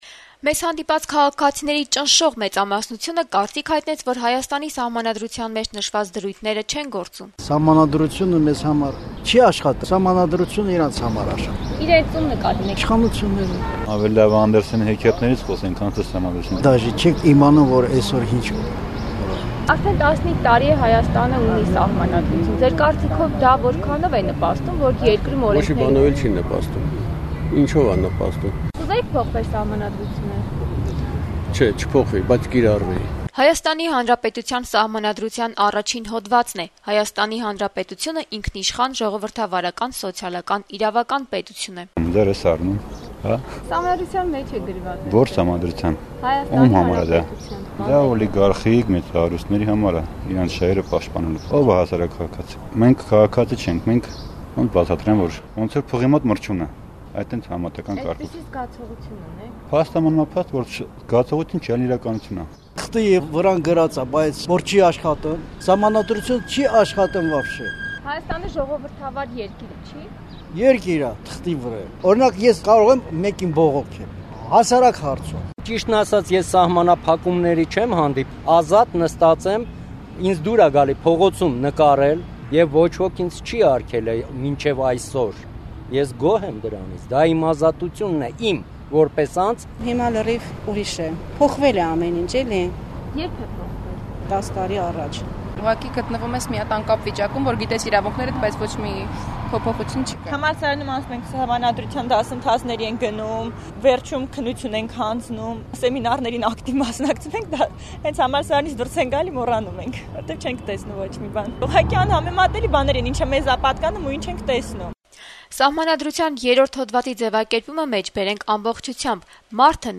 Հուլիսի 5-ին` նորանկախ Հայաստանի սահմանադրության ընդունման 15-րդ տարեդարձի օրը, Հայաստանի Հանրապետություն քաղաքացիները իրենց կարծիքն են հայտնում «Ազատություն» ռադիոկայանի թղթակցի հետ զրույցներում: